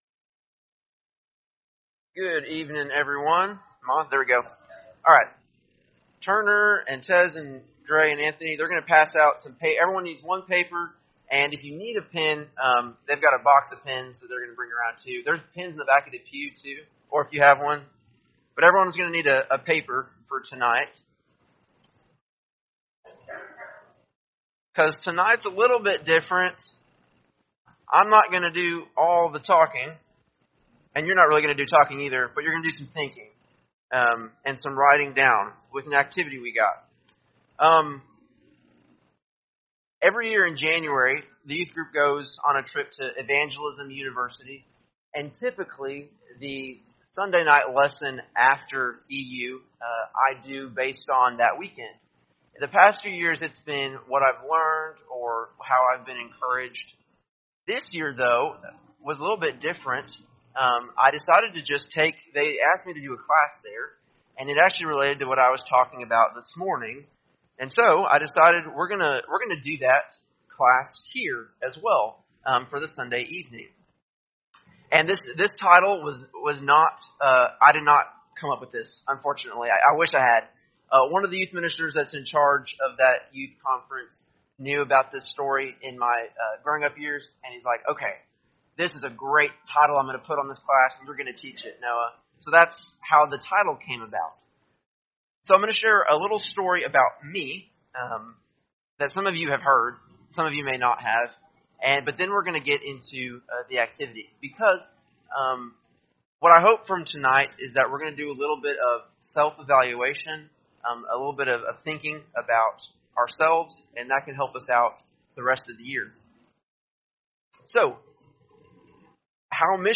1-26-25-Sunday-PM-Sermon.mp3